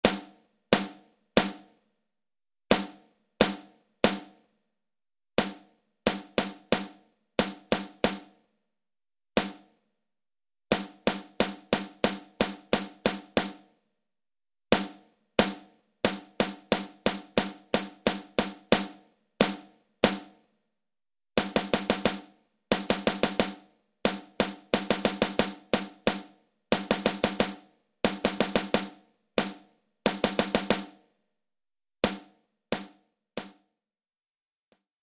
Oeuvre pour tambour seul.
Niveau : débutant.